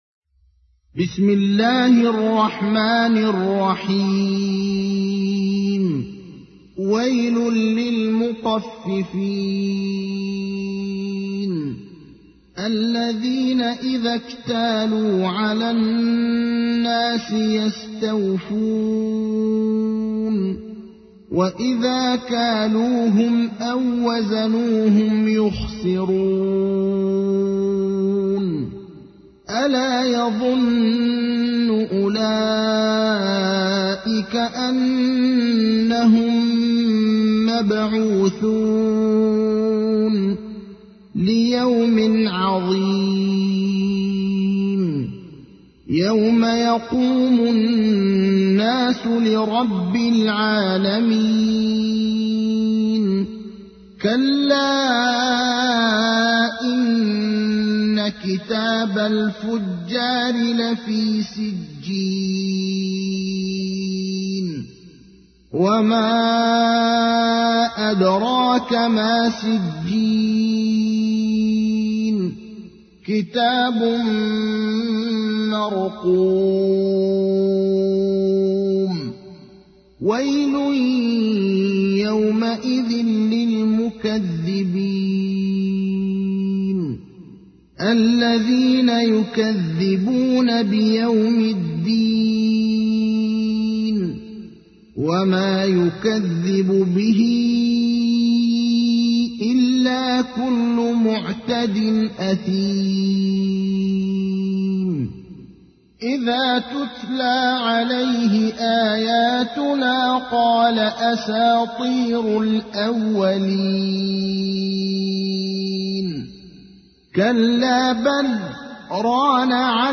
تحميل : 83. سورة المطففين / القارئ ابراهيم الأخضر / القرآن الكريم / موقع يا حسين